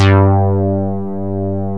MOOGBASS1 G3.wav